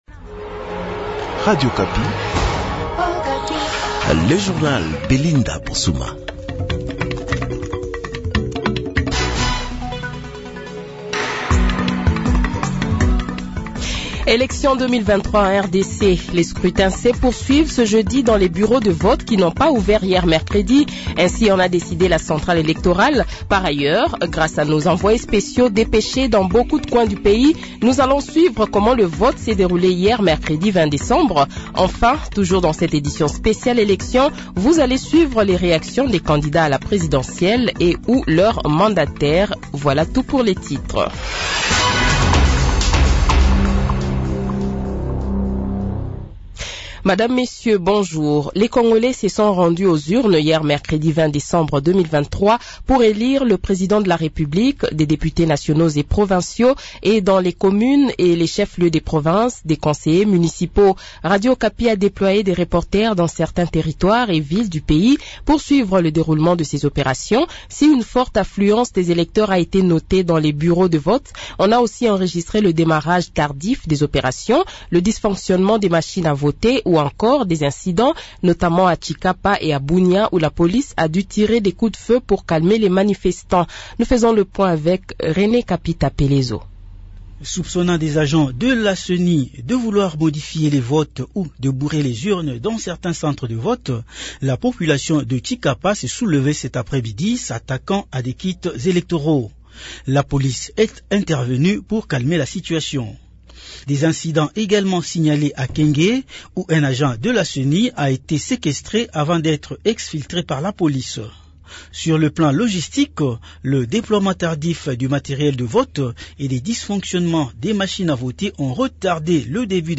Le Journal de 7h, 21 Decembre 2023 :